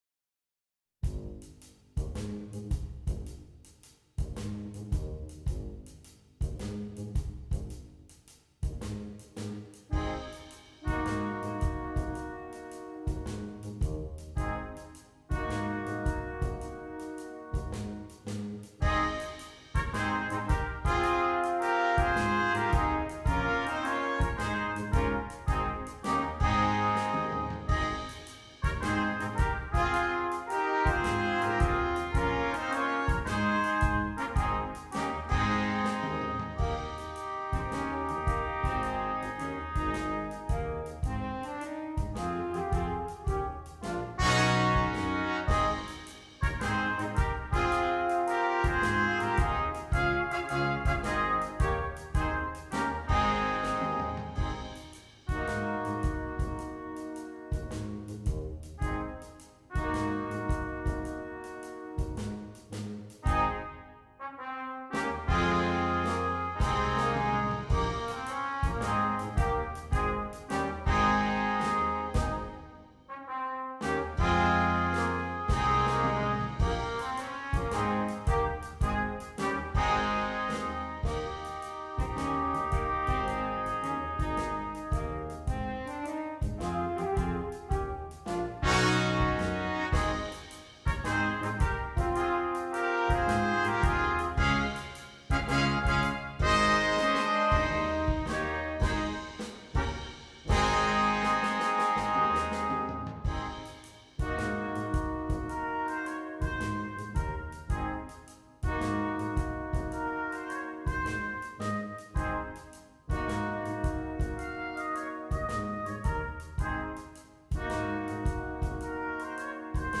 Brass Quintet (optional Drum Set)
Traditional Carol